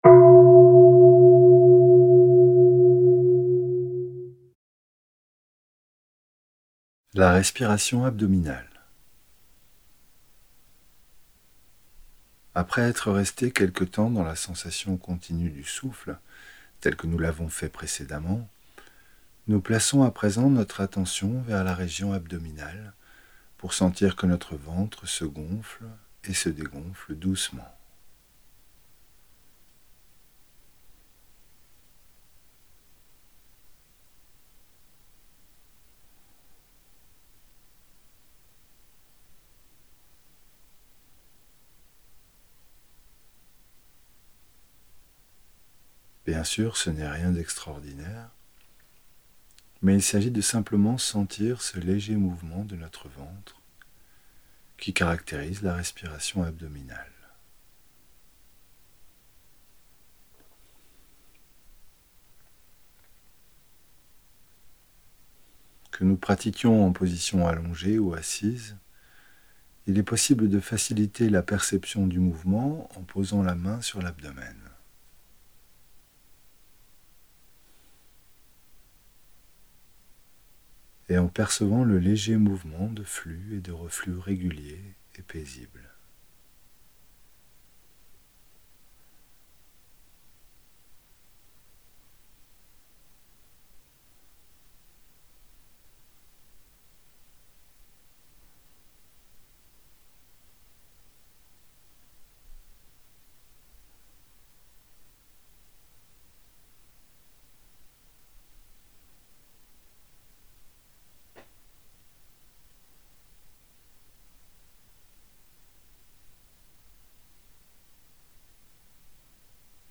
Audio homme
4.ETAPE-2-AUDIO-5-P13-HOMME.mp3